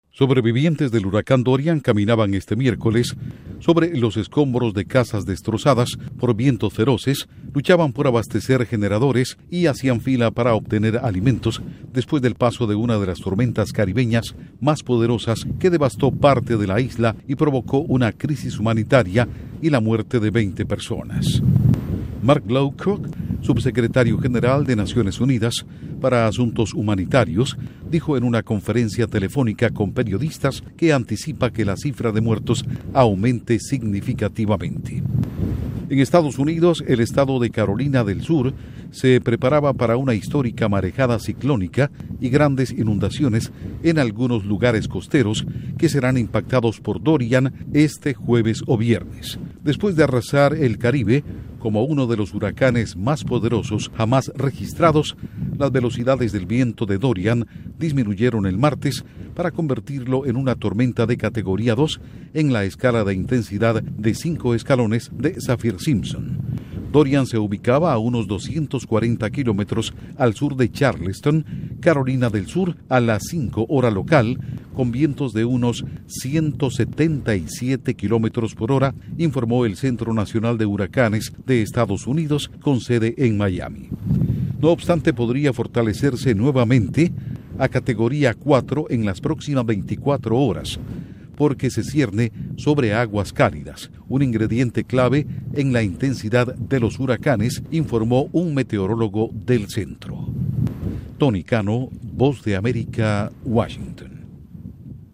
Costa de Estados Unidos se prepara para marejadas ciclónicas; 20 muertos en Bahamas tras huracán Dorian. Informa desde la Voz de América en Washington